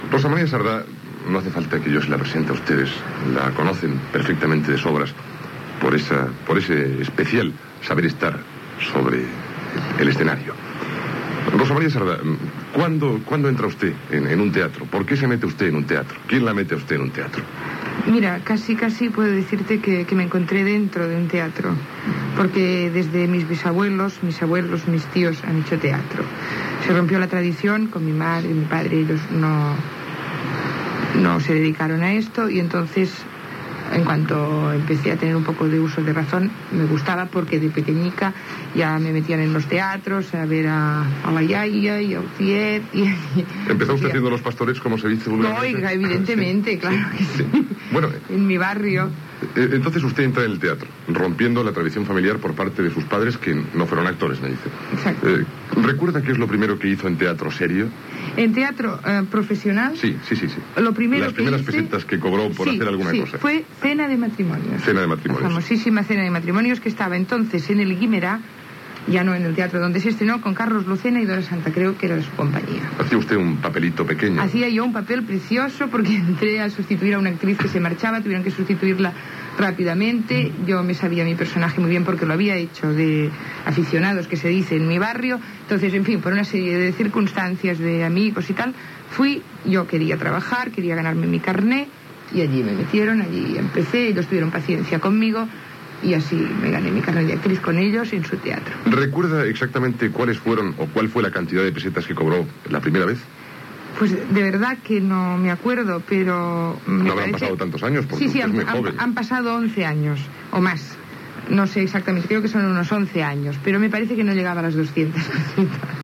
Entrevista a l'actriu Rosa Maria Sardà sobre els seus incis professionals